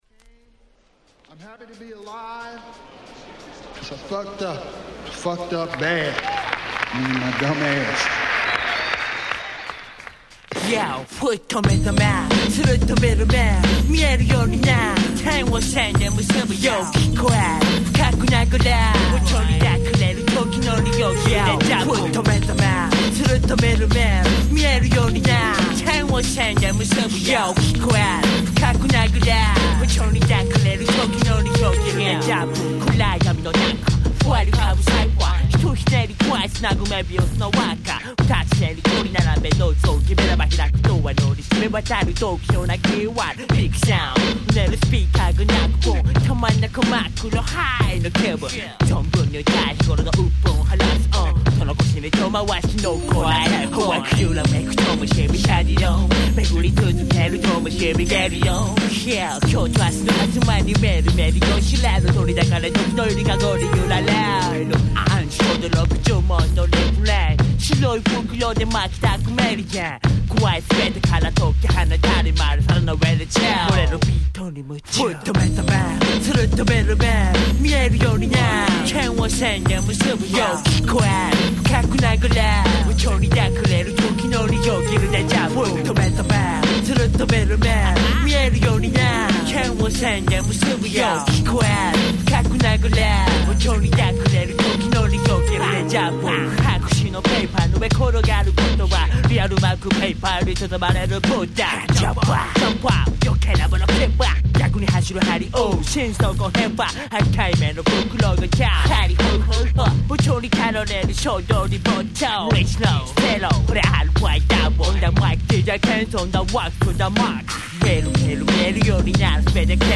97' Japanese Hip Hop Classic !!
スリリングな展開のマイクリレーは今聴いてもガンガンに首が動いてしまいます。